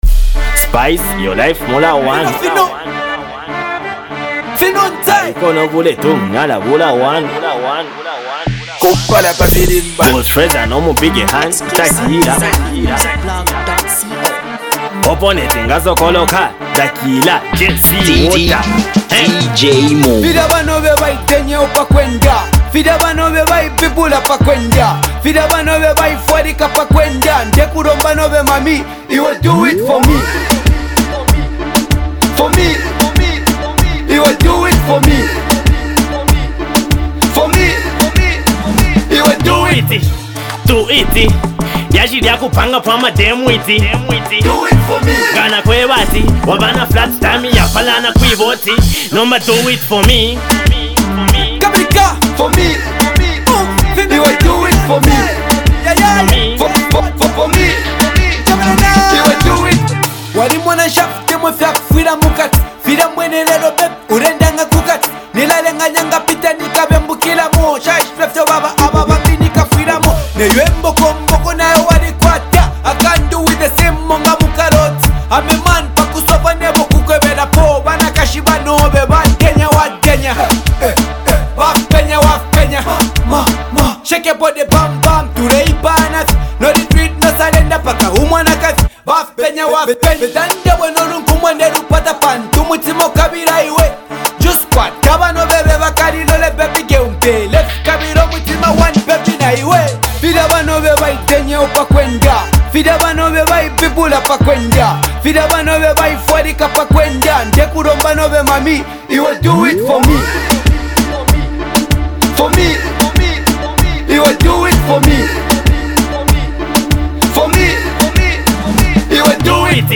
high-energy street anthem
smooth delivery
From catchy hooks to a bouncing rhythm